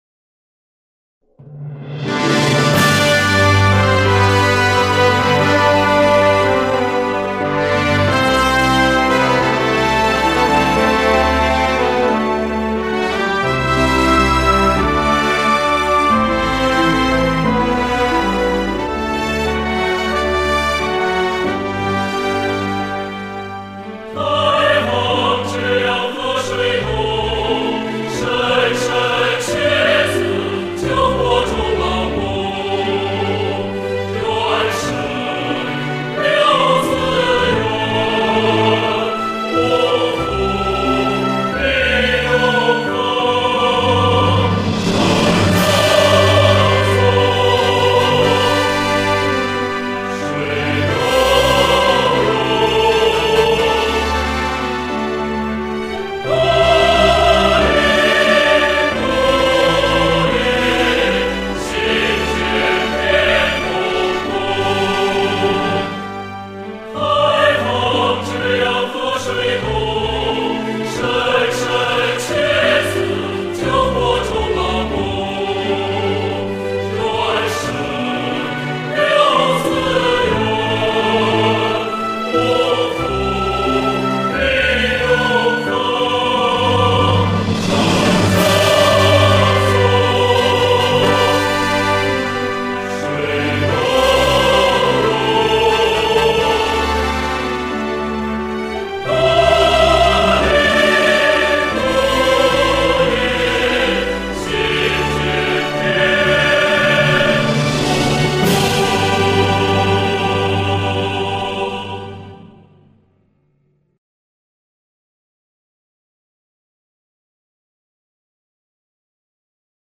校歌
说明：1.重要会议、仪式等场合使用校歌单遍版演唱曲目；